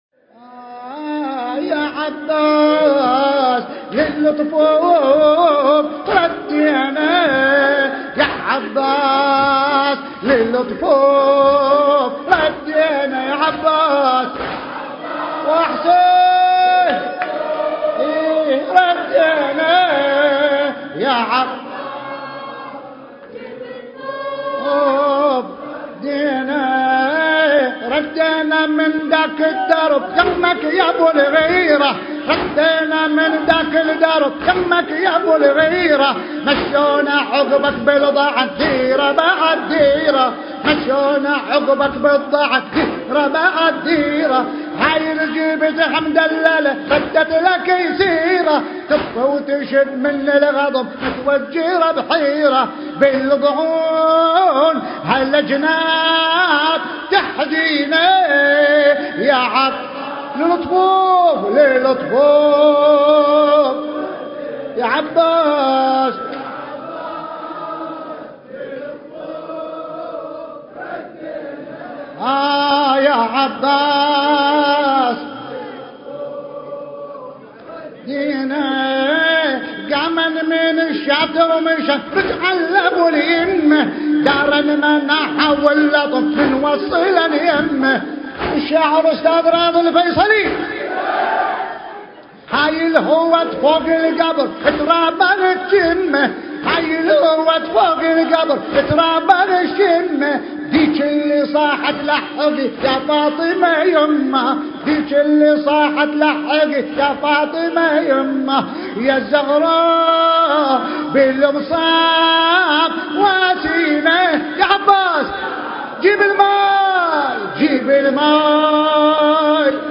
المراثي
الحجم: 1.06 MB الشاعر: راضي الفيصلي المكان: واعية أم البنين ع – مأتم بن زبر 1438 للهجرة